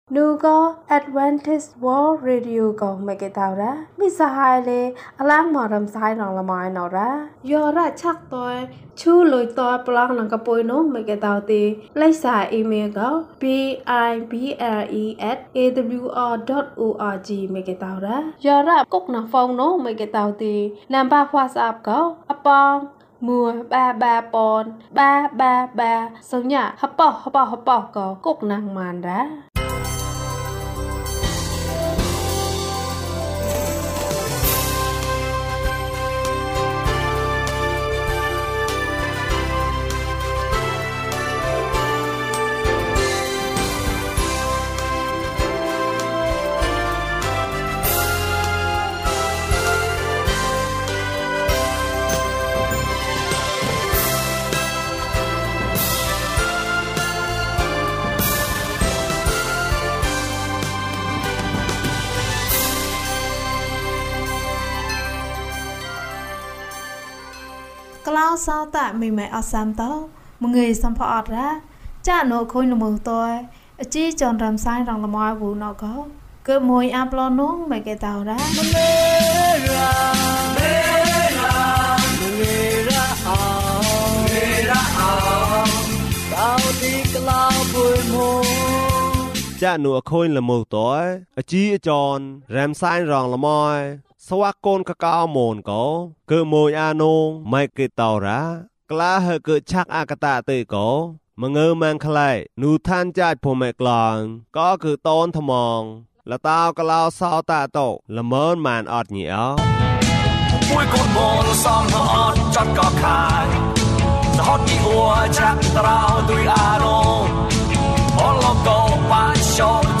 အခြားနေ့များအတွက် ဆုတောင်းပါ။ ကျန်းမာခြင်းအကြောင်းအရာ။ ဓမ္မသီချင်း။ တရားဒေသနာ။